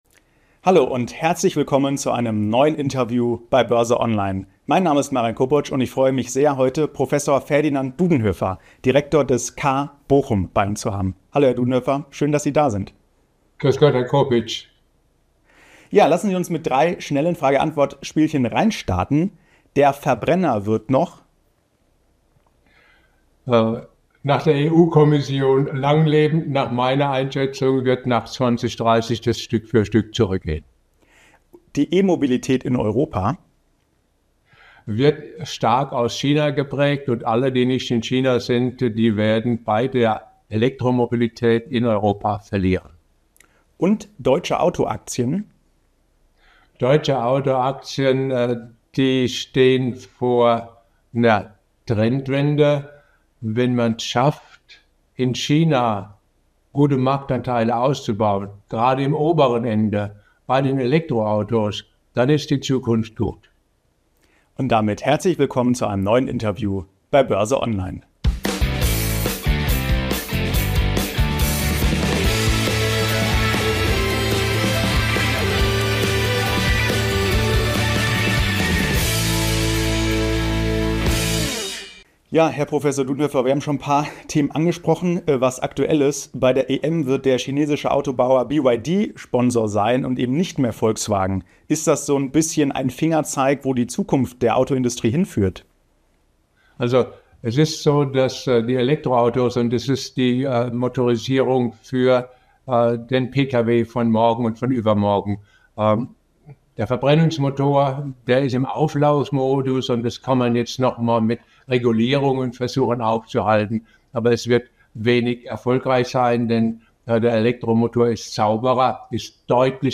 Was die Politik jetzt konkret tun muss, um den Auto-Standort Deutschland zu retten, wie es um die Aktien von Volkswagen, BMW und Mercedes gestellt ist und warum die Zukunft des Autos in China entschieden wird, das erfahren Sie jetzt im Interview mit Professor Ferdinand Dudenhöffer, Direktor des CAR Bochum, im neuen Interview bei BÖRSE ONLINE.